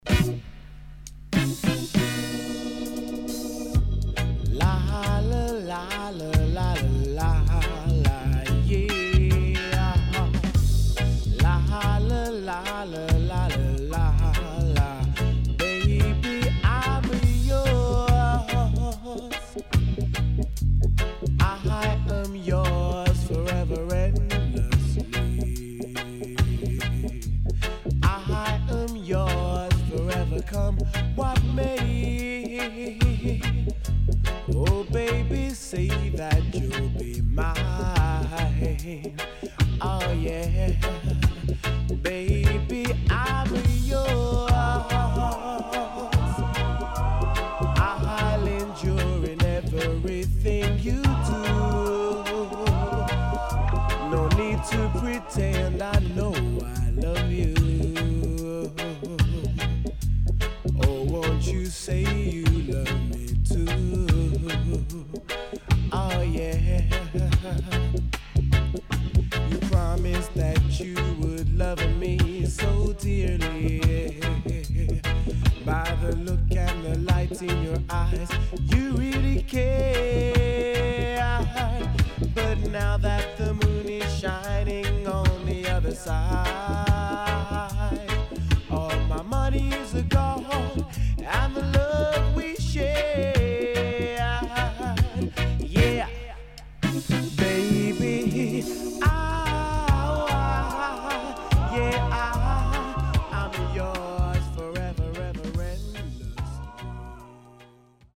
渋Lovers Vocal.W-Side Good
SIDE A:少しチリノイズ入りますが良好です。